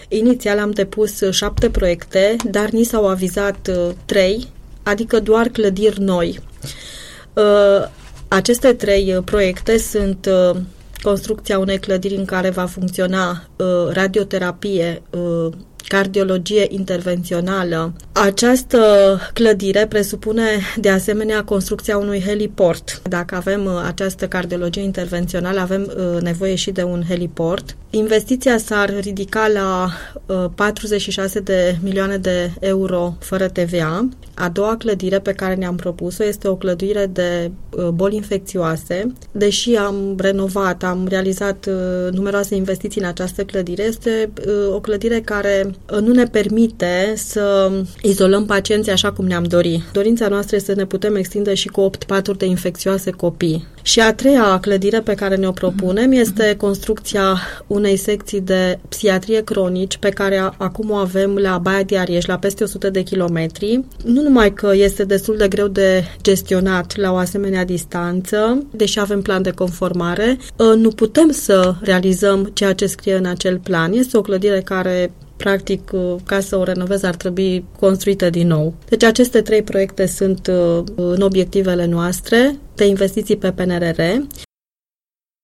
Prezentă în studioul Unirea FM